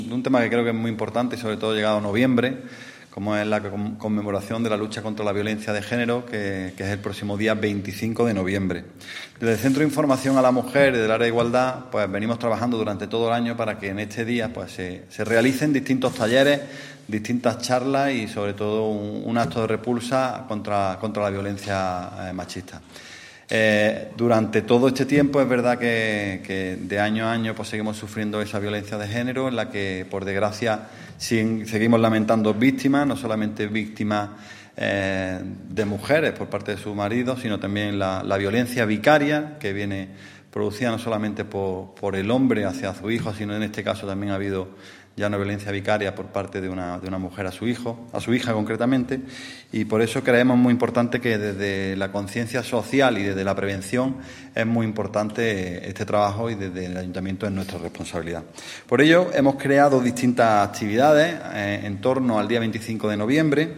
El teniente de alcalde delegado de Programas Sociales, Igualdad, Cooperación Ciudadana y Vivienda, Alberto Arana, ha informado en la mañana de hoy en rueda de prensa de las actividades que en nuestra ciudad conmemoran el Día Internacional de la Eliminación de la Violencia contra la Mujer en torno al próximo viernes 25 de noviembre, fecha establecida por la ONU como recordatorio de las hermanas Mirabal, tres activistas políticas que fueron brutalmente asesinadas en 1960 por orden del gobernante dominicano Rafael Trujillo.
Cortes de voz